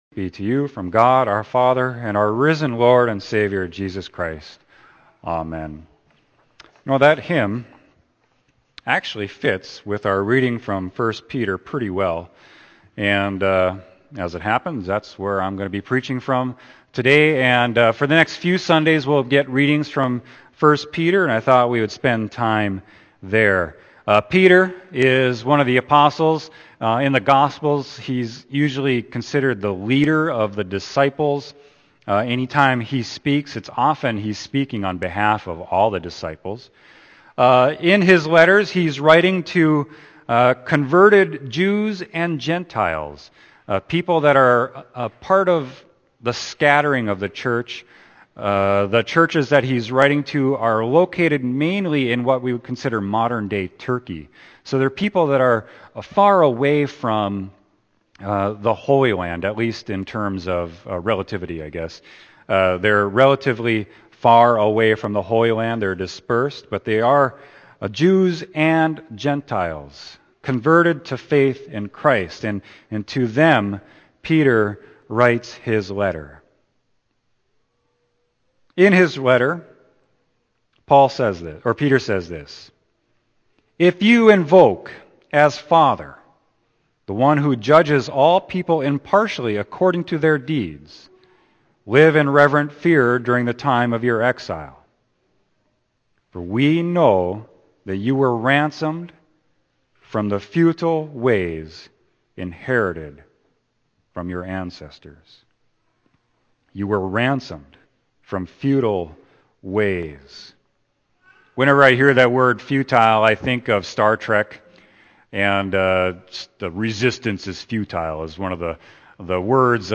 Scriptures: Acts 2:14a, 36-41; Psalm 116; 1 Peter 1:17-23; Luke 24:13-35 Sermon: 1 Peter 1.17-23